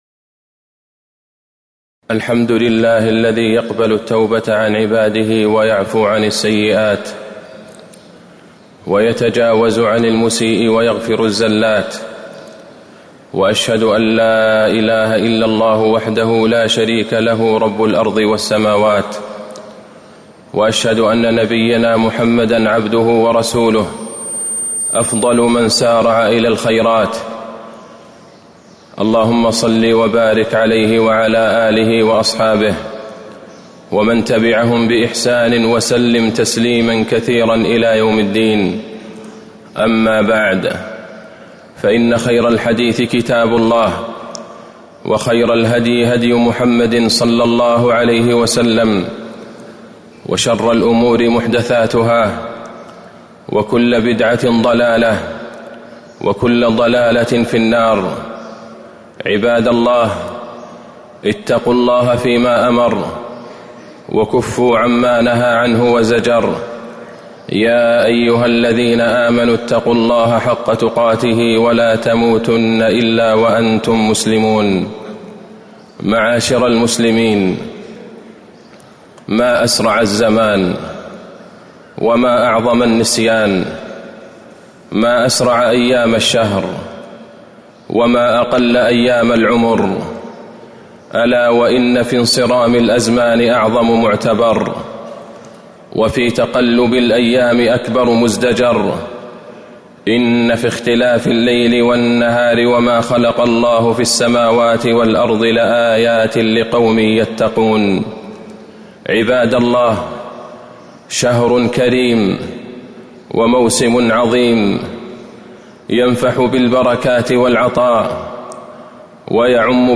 تاريخ النشر ٢٨ رمضان ١٤٤٣ هـ المكان: المسجد النبوي الشيخ: فضيلة الشيخ د. عبدالله بن عبدالرحمن البعيجان فضيلة الشيخ د. عبدالله بن عبدالرحمن البعيجان ختام رمضان